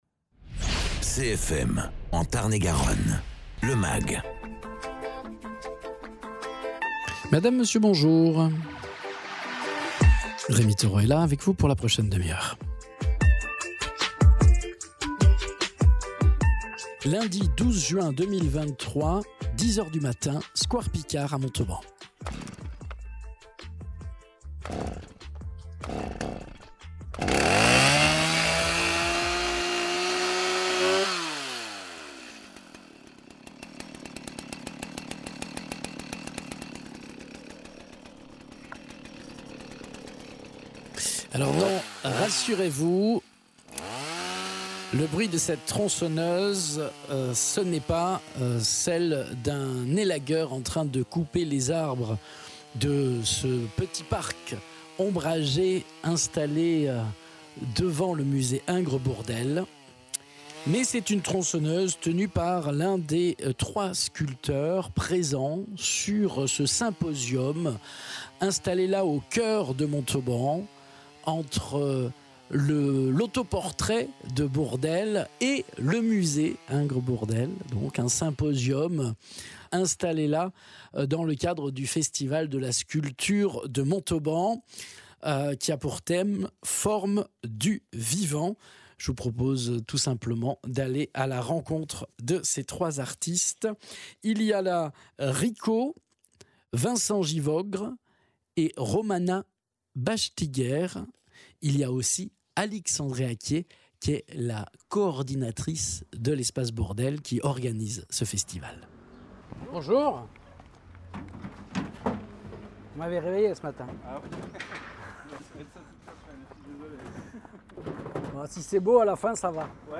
Reportage au square Picard à Montauban sur le symposium de sculpture du festival